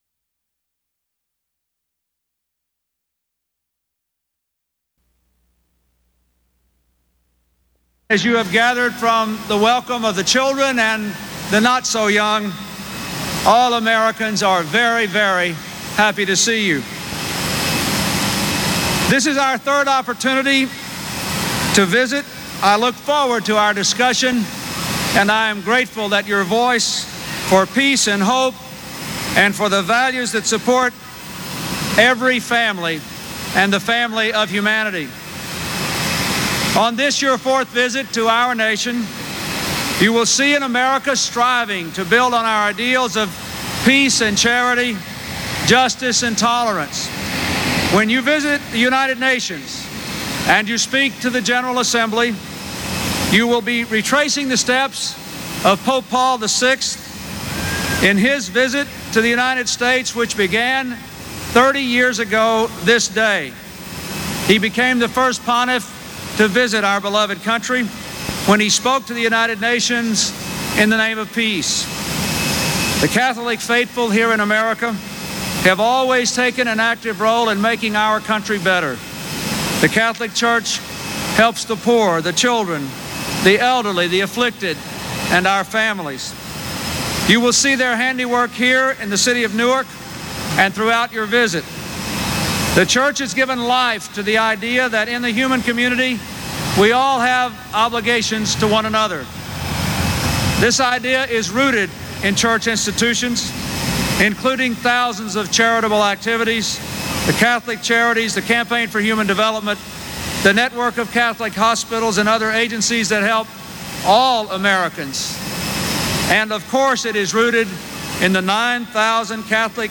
U.S. President Bill Clinton welcomes Pope John Paul II to the United States at Newark Airport